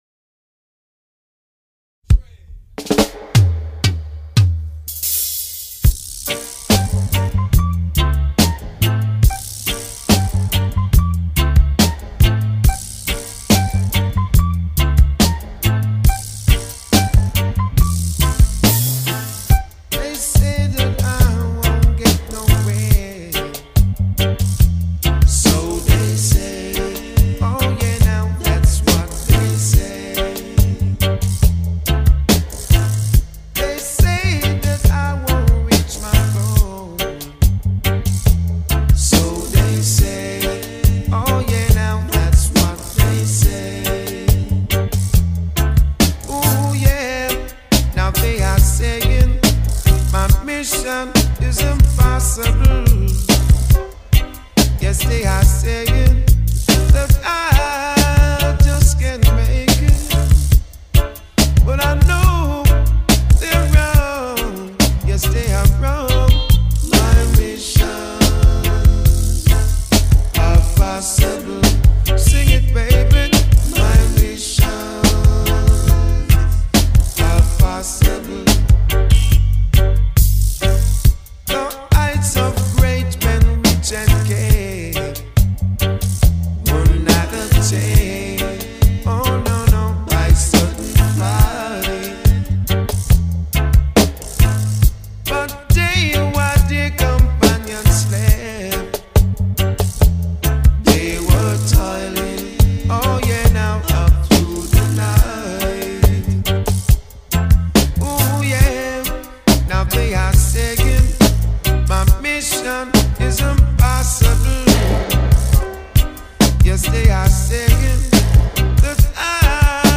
Strictly the true original reggae music